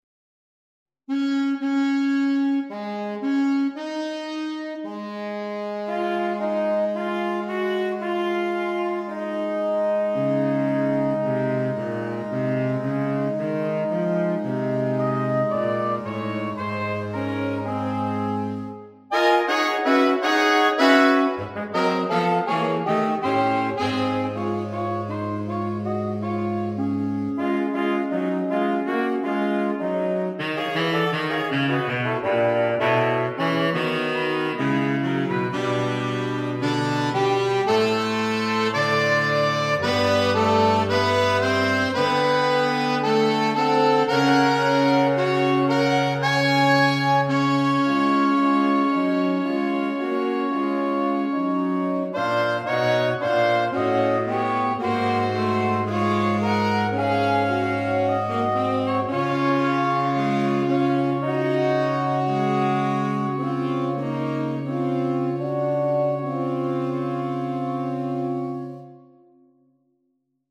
(Irreverent Big Band Sounds)